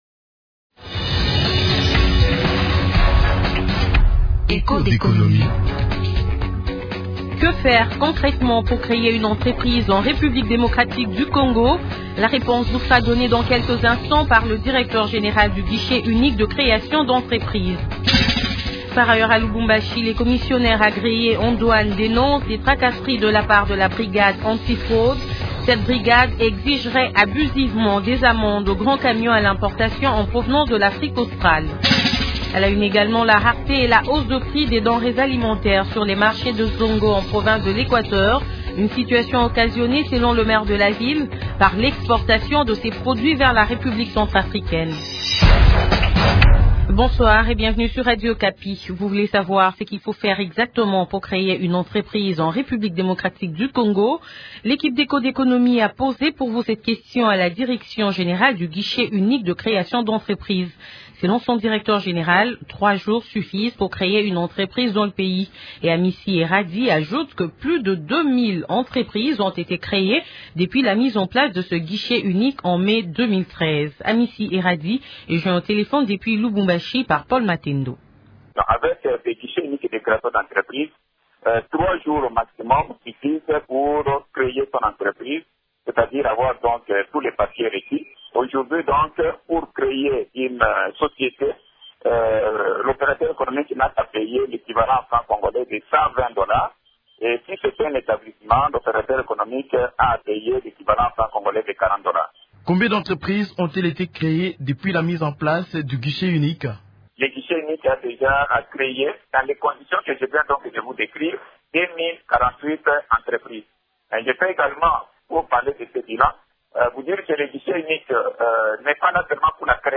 Ce qui explique la hausse observée actuellement sur le marché, explique Michel Siazo le maire de Zongo.